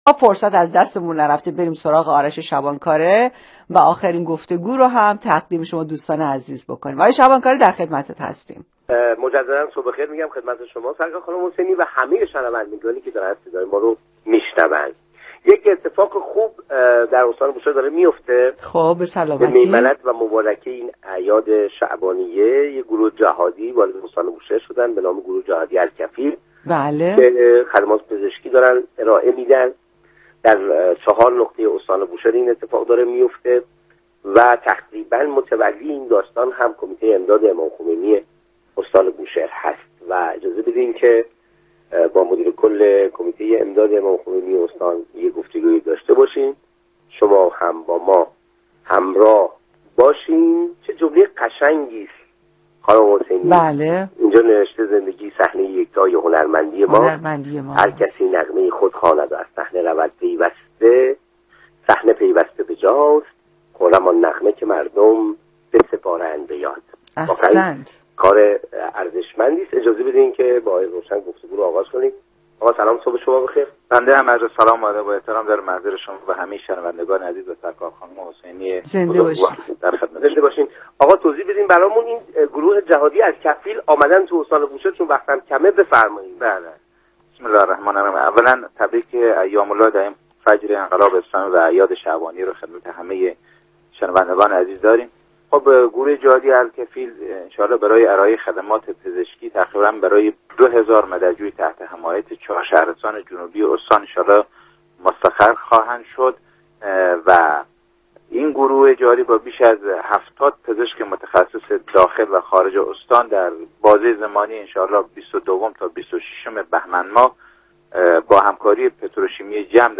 مصاحبه رادیویی مدیر کل کمیته امداد بوشهر با شبکه استانی رادیو در خصوص حضور گروه جهادی الکفیل در استان
مصاحبه رادیویی
مصاحبه رادیویی مدیرکل -الکفیل.mp3